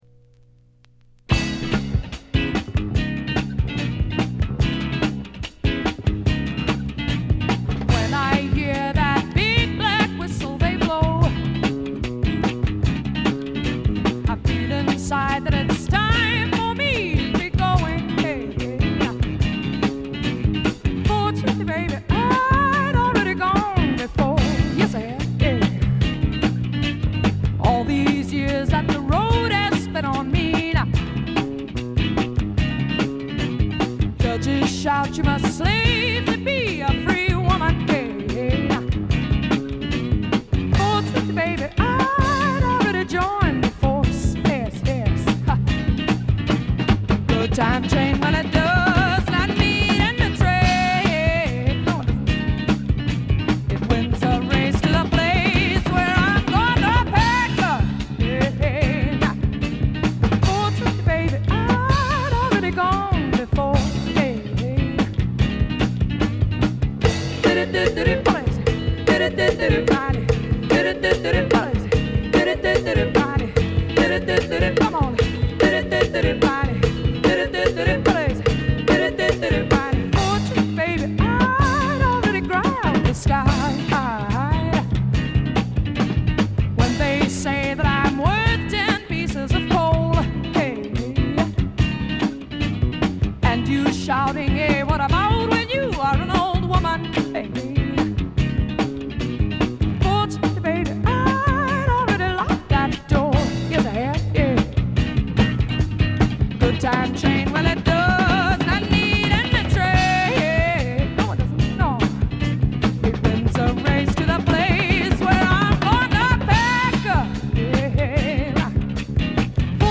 試聴曲は現品からの取り込み音源です。
Recorded At: The Record Plant East, New York City.